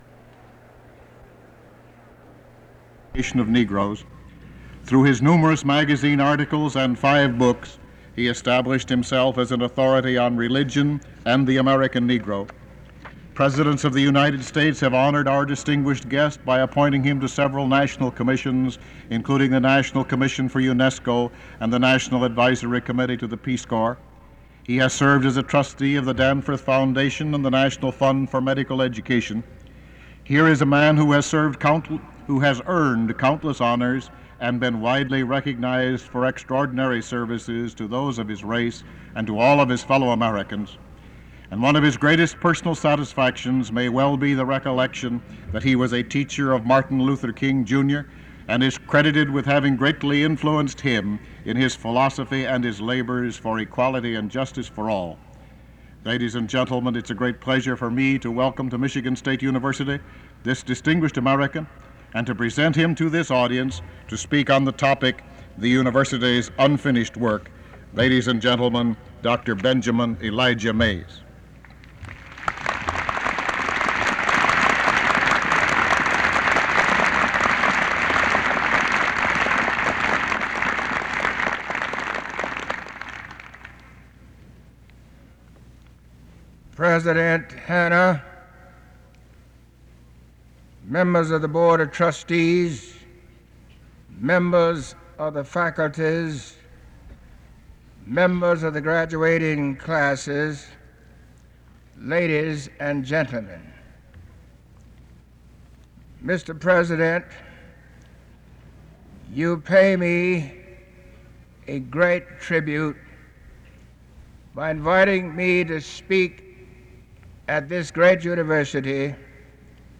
Commencement Address, Summer 1968
MSU President John A. Hannah presents Dr. Benjamin Elijah Mays, President Emeritus of Morehouse College, to speak at the June 1968 commencement ceremonies.
Original Format: Open reel audio tape